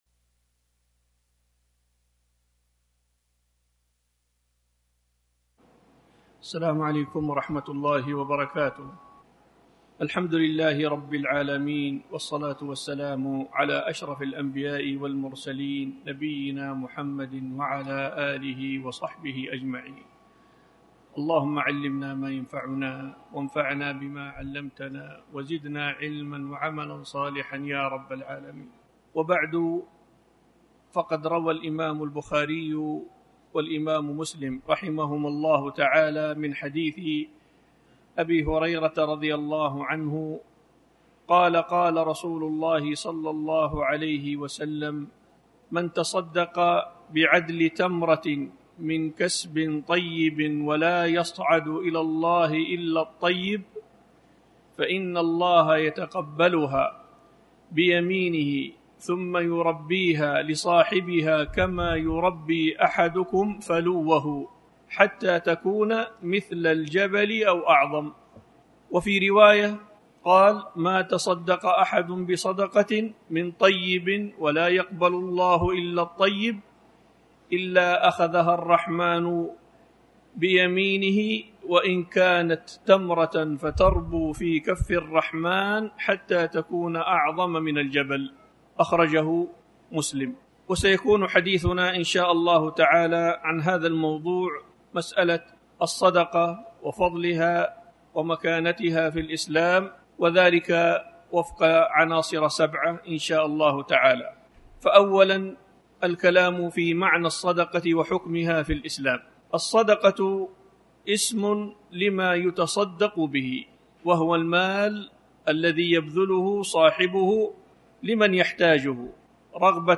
تاريخ النشر ٢٠ شعبان ١٤٣٩ المكان: المسجد الحرام الشيخ